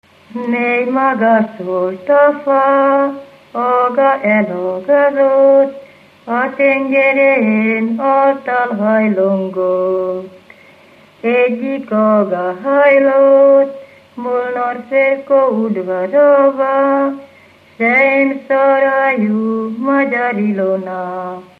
Felföld - Nyitra vm. - Ghymes
Műfaj: Szentiváni ének
Stílus: 5. Rákóczi dallamkör és fríg környezete
Kadencia: 5 (5) 1 1